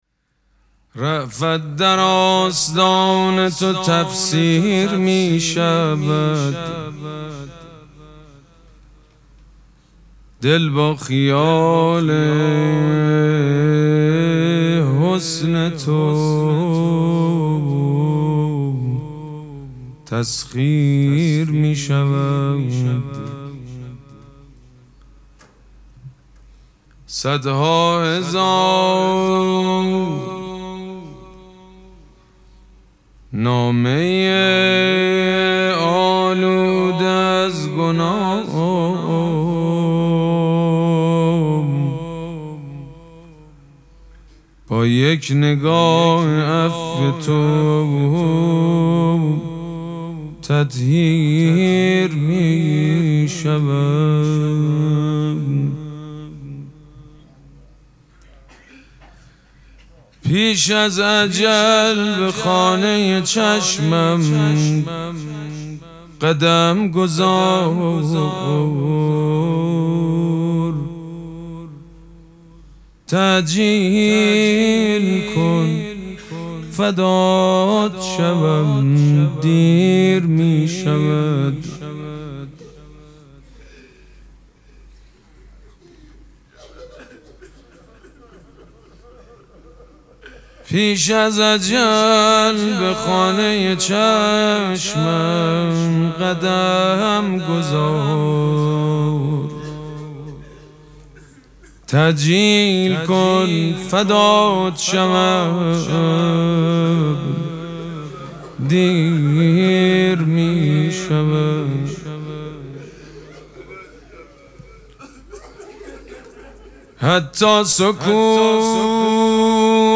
مراسم مناجات شب هشتم ماه مبارک رمضان
شعر خوانی
حاج سید مجید بنی فاطمه